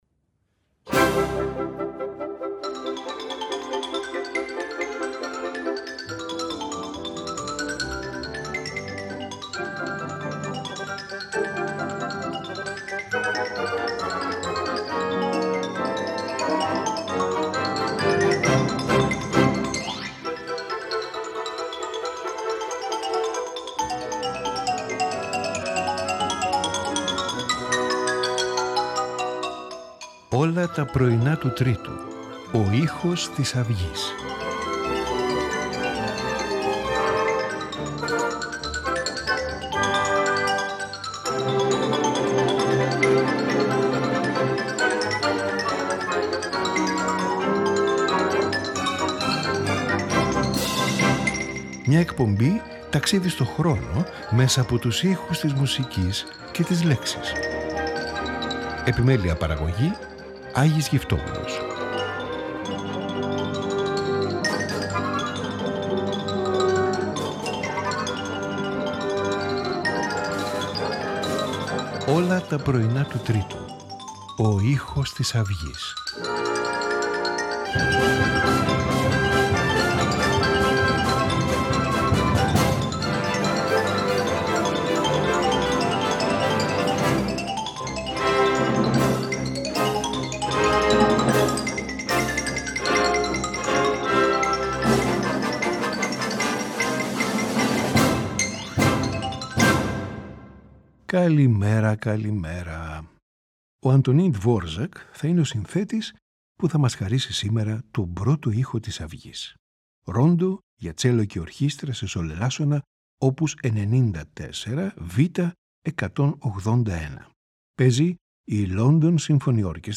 cello and orchestra